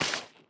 wet_grass3.ogg